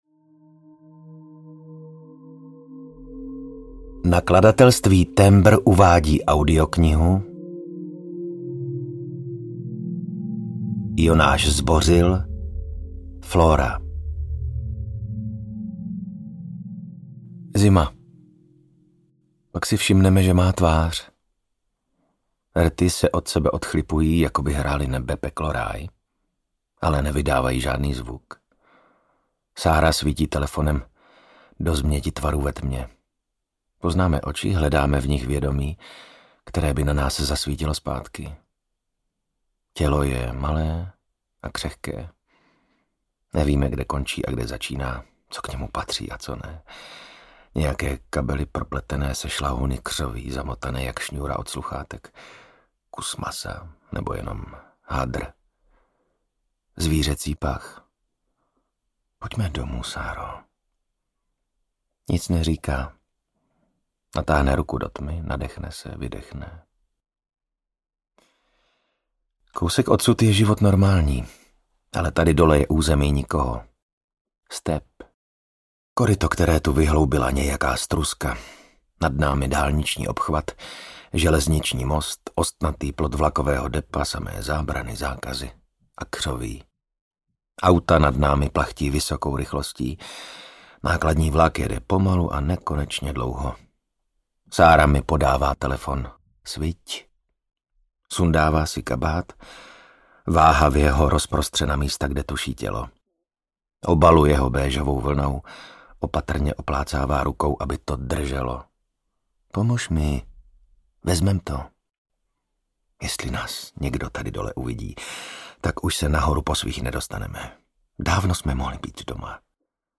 Flora audiokniha
Ukázka z knihy
• InterpretVasil Fridrich